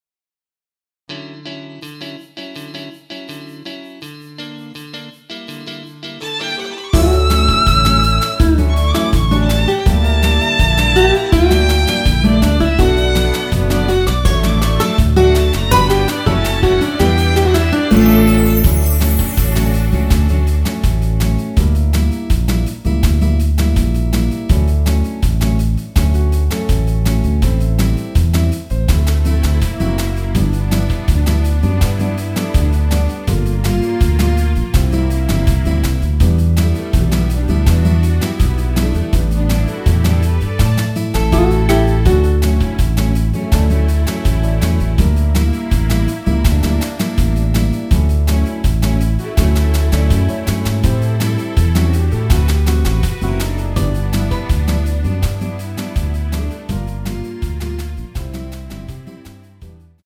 MR 입니다.
원키에서(+1)올린 MR입니다.
◈ 곡명 옆 (-1)은 반음 내림, (+1)은 반음 올림 입니다.
앞부분30초, 뒷부분30초씩 편집해서 올려 드리고 있습니다.